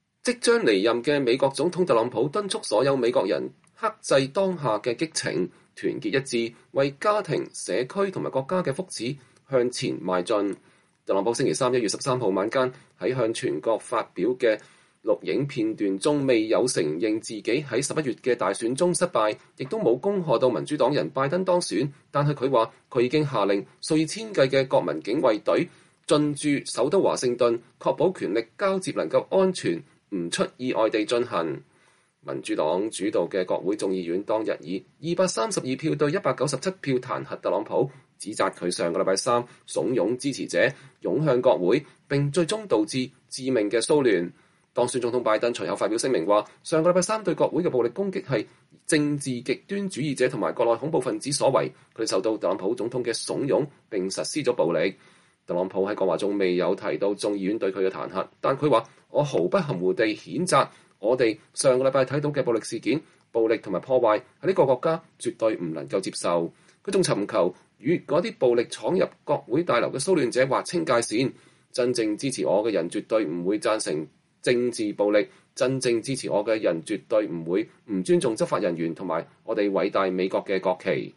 特朗普向全國發表講話：譴責暴力促團結一致向前邁進